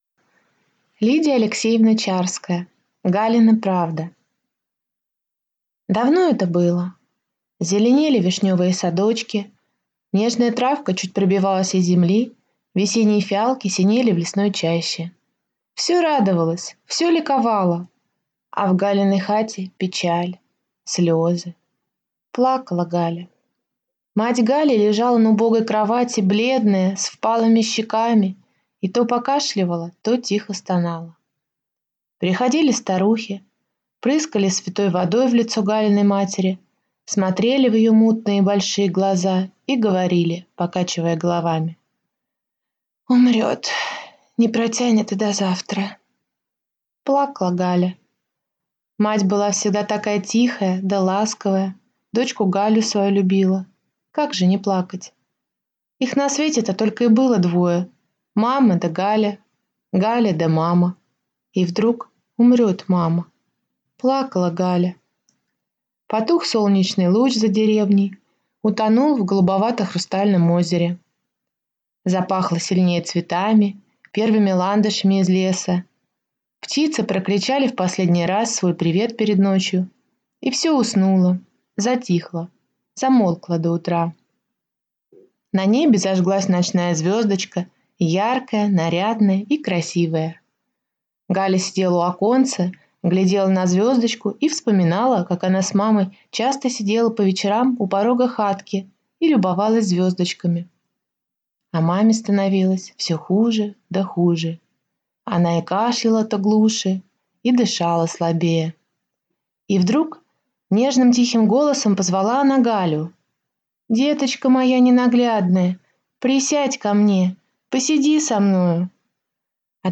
Аудиокнига Галина правда | Библиотека аудиокниг